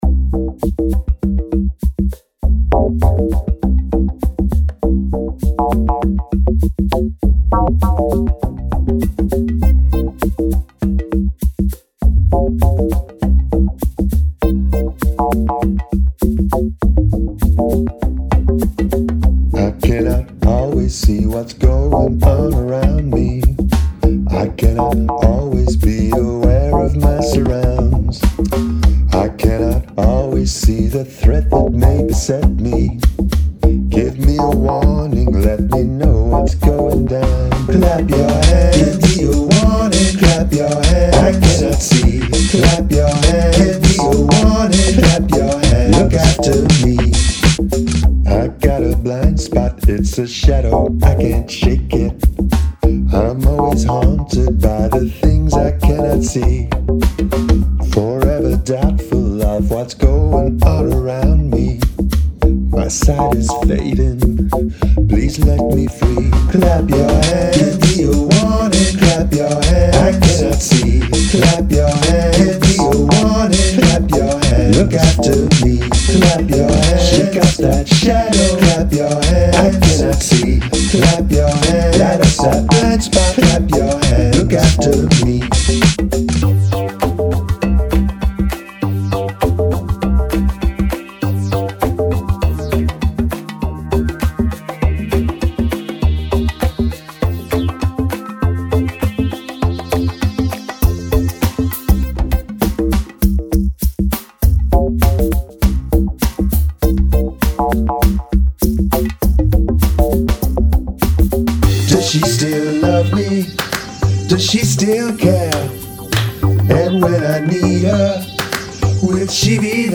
Hand percussion